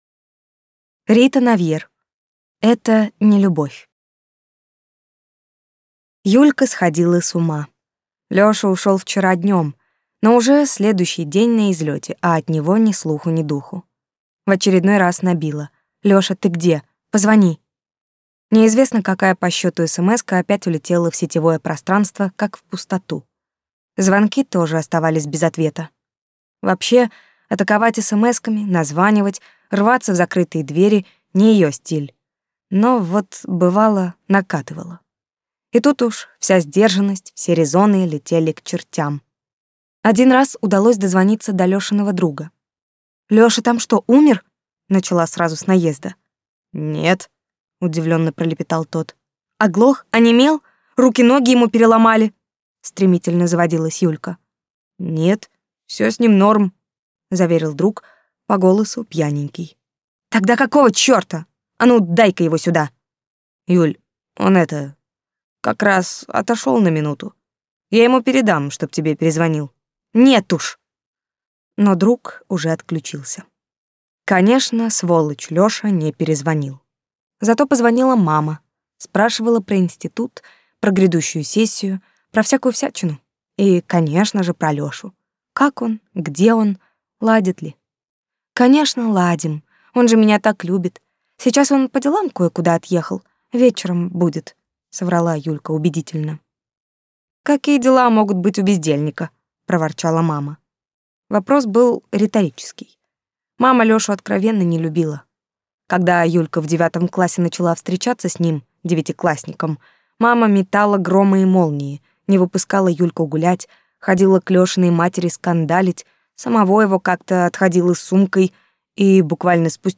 Аудиокнига Это не любовь | Библиотека аудиокниг